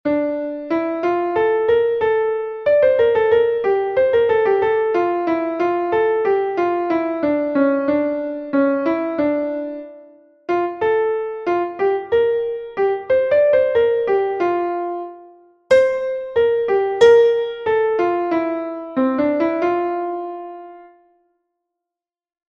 - Exercise 2: D minor (bars 1 to 8) and F major (bars 9 to 16)..
melodic_reading_2.mp3